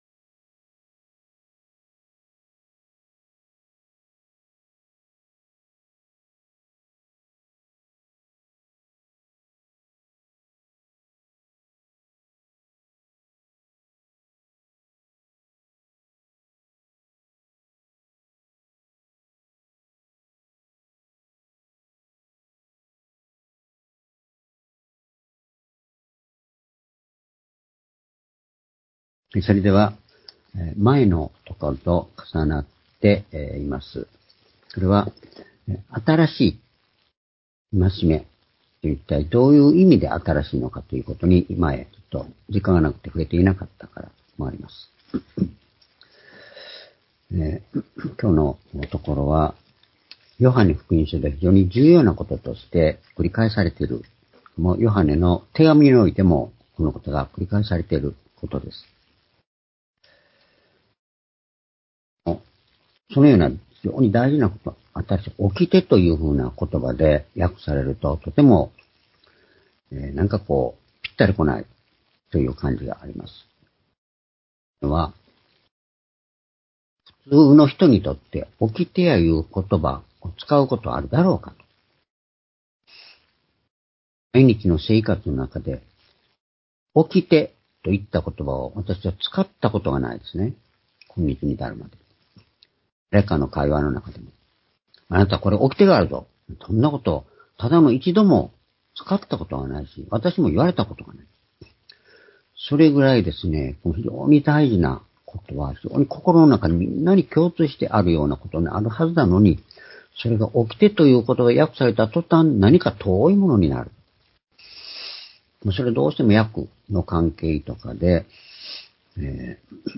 「どこから来てどこへ行くのか」-ヨハネ13章３４節～３８節―２０２４年2月4日（主日礼拝）
主日礼拝日時 ２０２４年2月4日（主日礼拝） 聖書講話箇所 「どこから来てどこへ行くのか」 ヨハネ13章３４節～３８節 ※視聴できない場合は をクリックしてください。